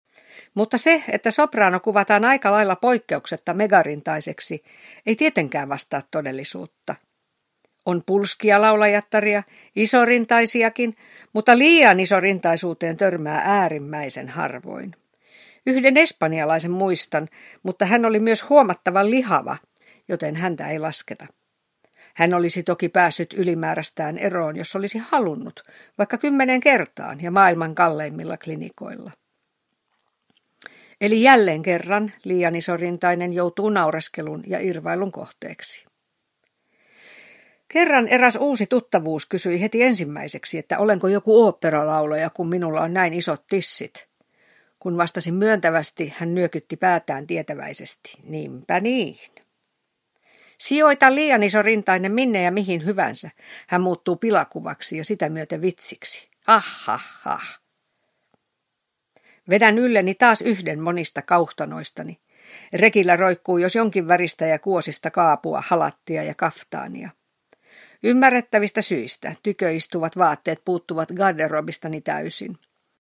Leenu, Liinu ja Tiinu – Ljudbok